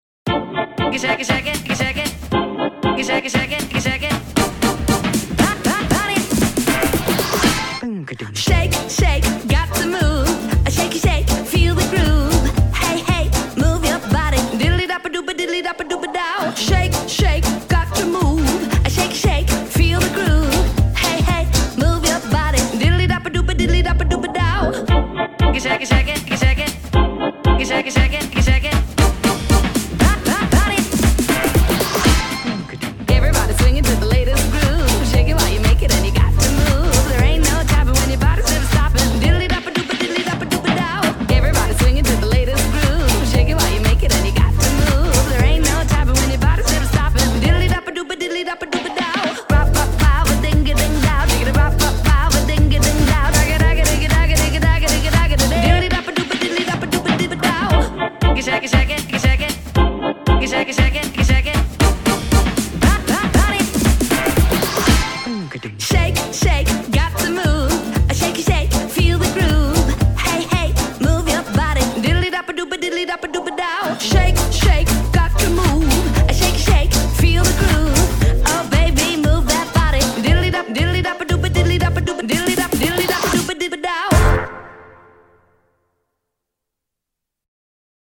electro-swing